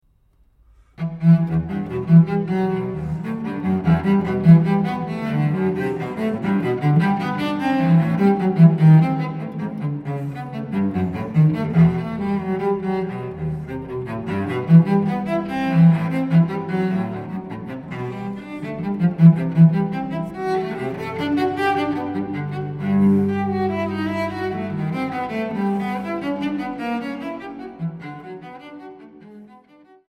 Transkiptionen für Violoncello